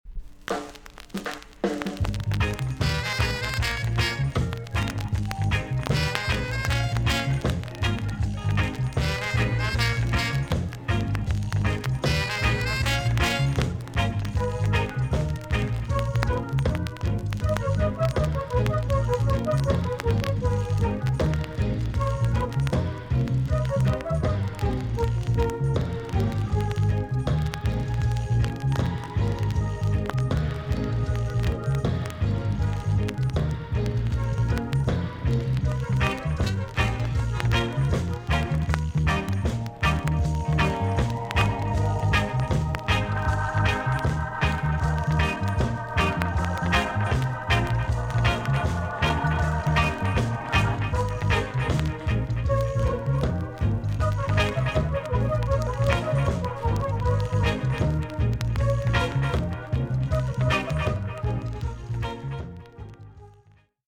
TOP >SKA & ROCKSTEADY
VG ok チリノイズが入ります。